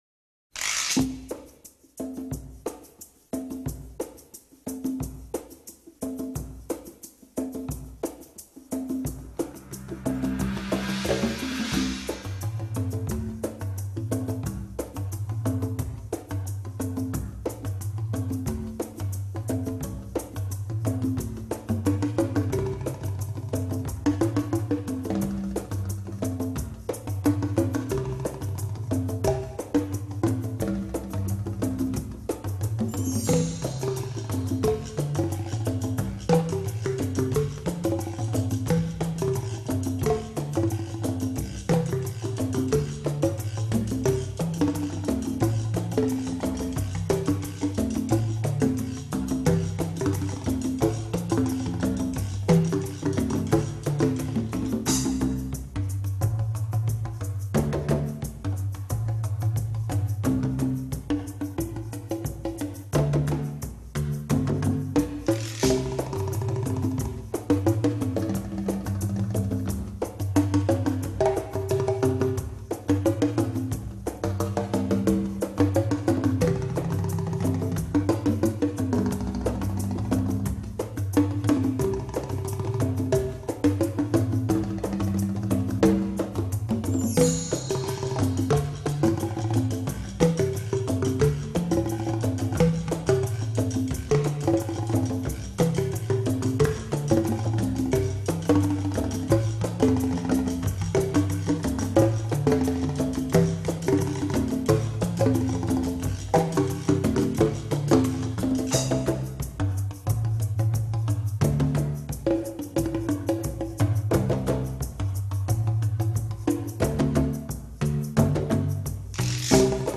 Palabras esdrújulas (con música de fondo).mp4